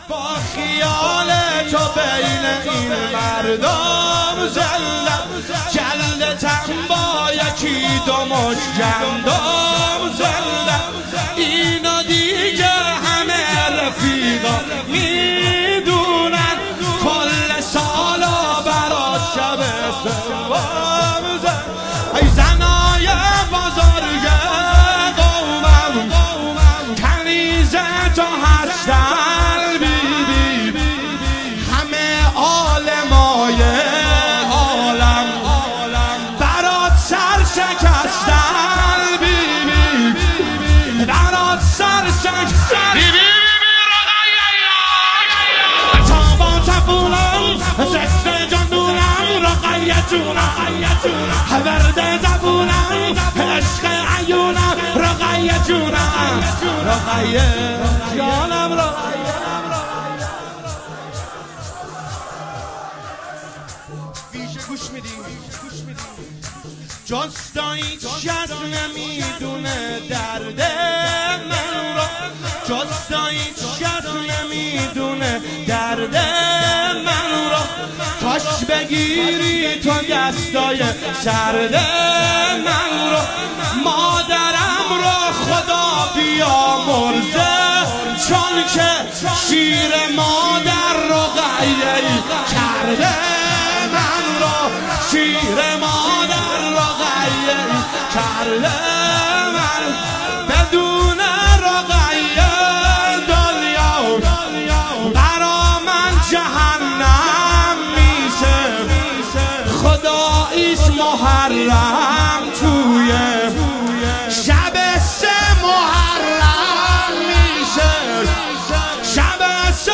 ویژه شب سوم محرم